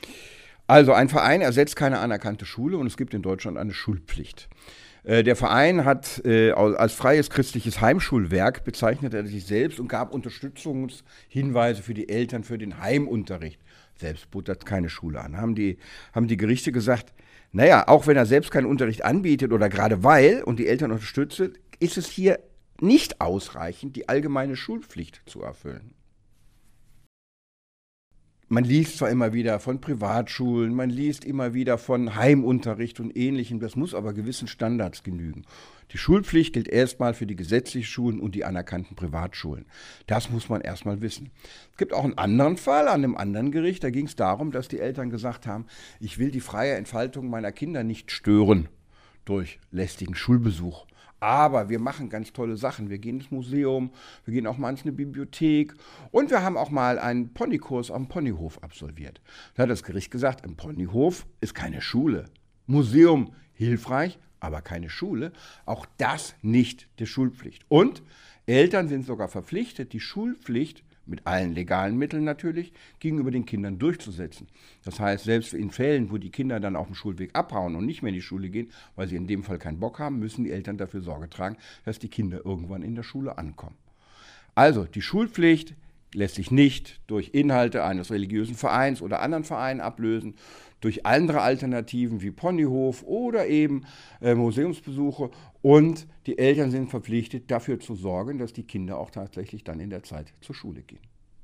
Kollegengespräch: Heimunterricht aus religiösen Gründen – Eltern scheitern vor Gericht – Vorabs Medienproduktion